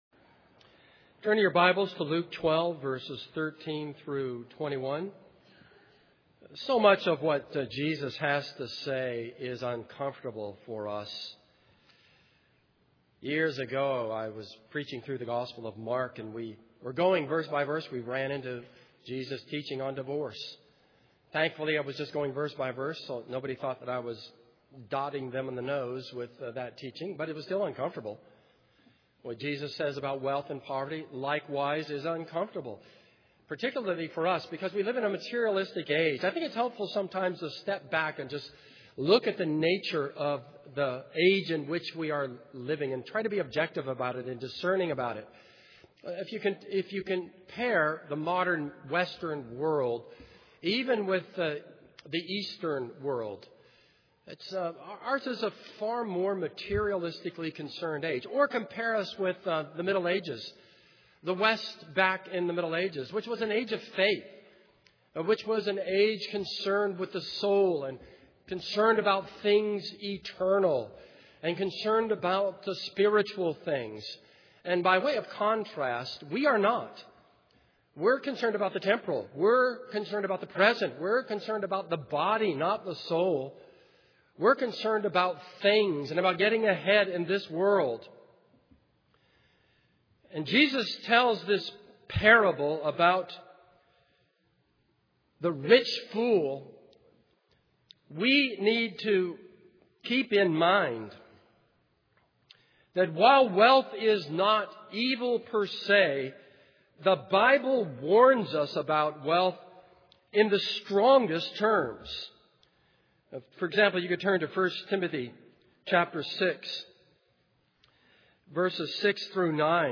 This is a sermon on Luke 12:13-21.